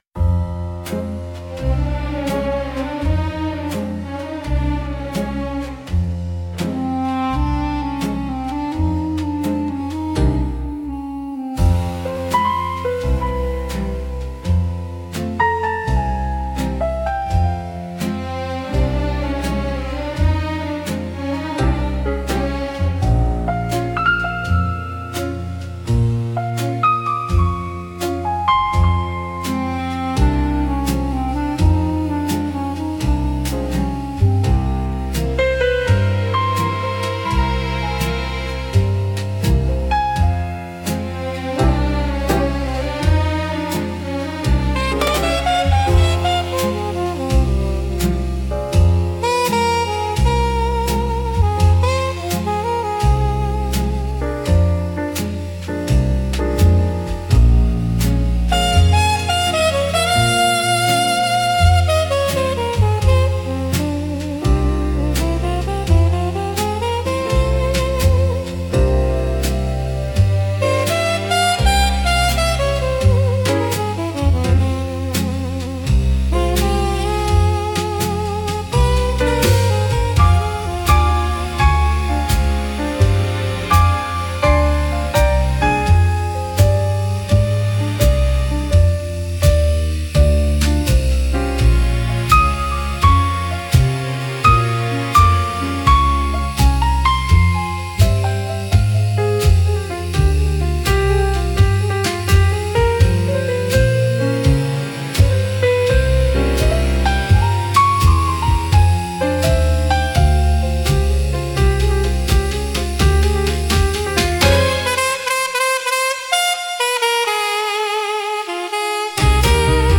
música e arranjo: IA) instrumental 9